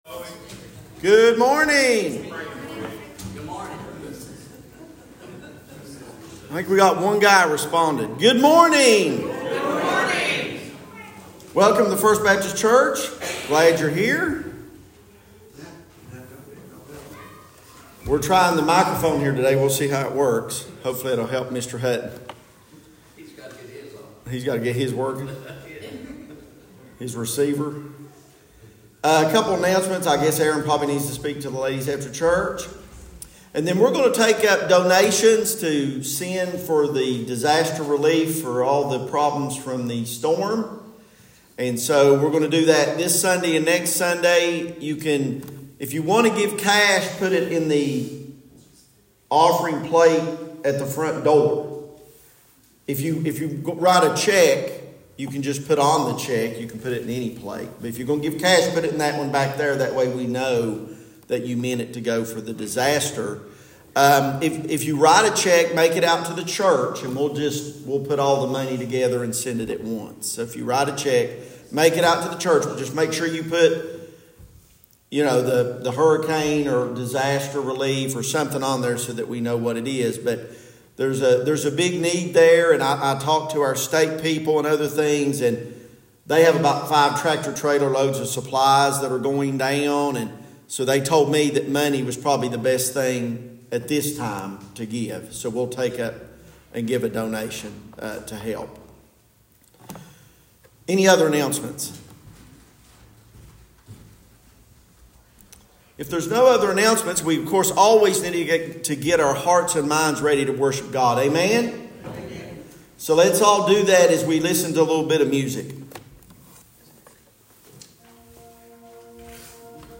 Sermons | First Baptist Church of Logan